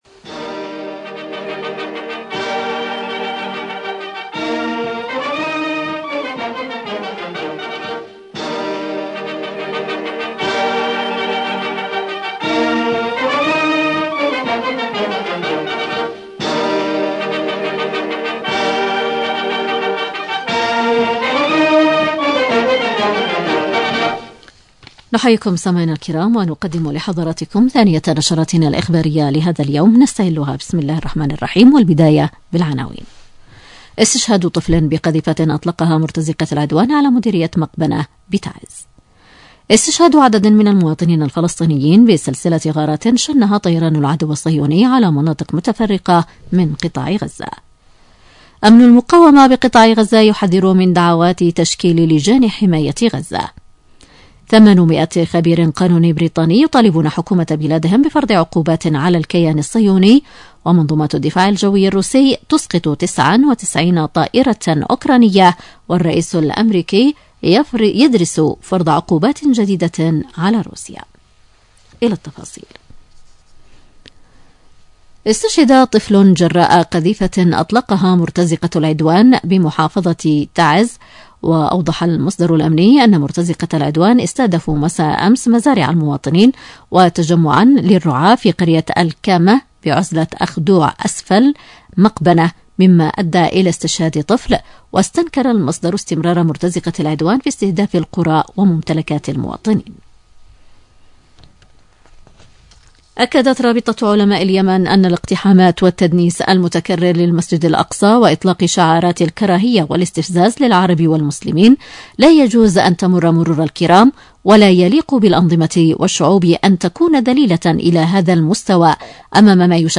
نشرة الحادية عشرة